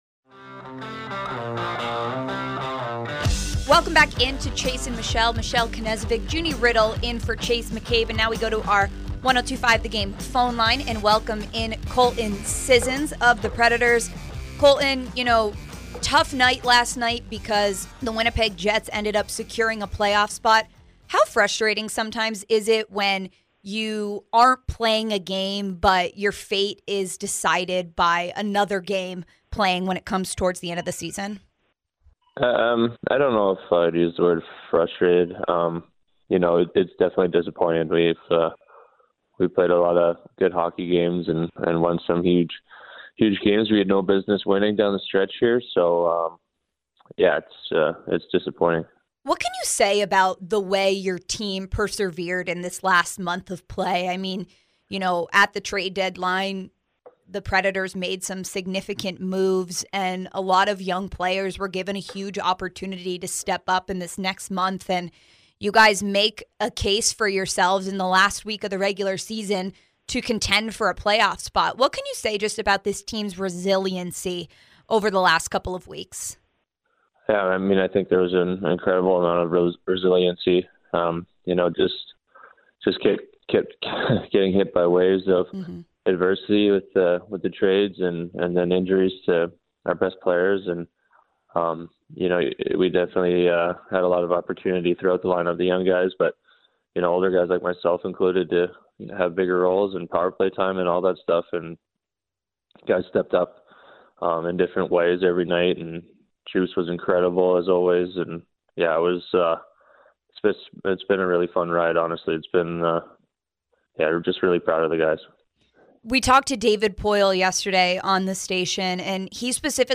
Colton Sissons Interview (4-12-23)